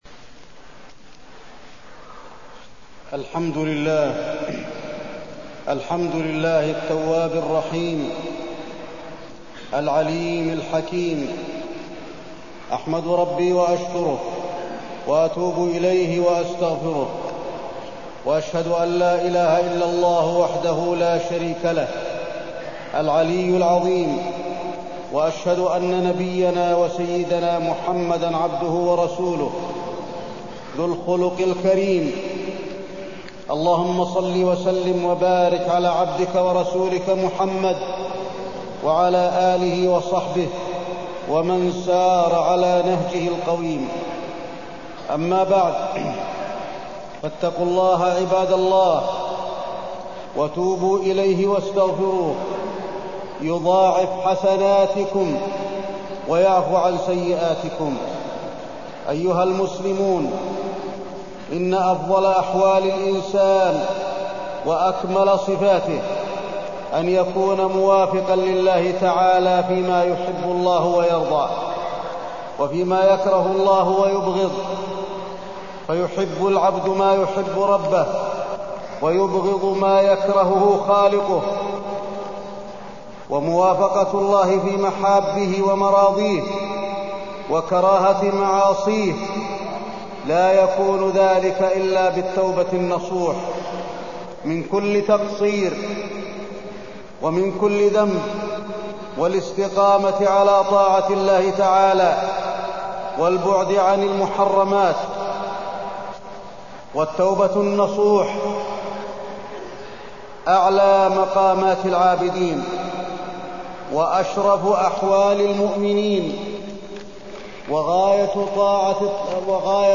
تاريخ النشر ٥ ربيع الأول ١٤٢٣ هـ المكان: المسجد النبوي الشيخ: فضيلة الشيخ د. علي بن عبدالرحمن الحذيفي فضيلة الشيخ د. علي بن عبدالرحمن الحذيفي التوبة النصوح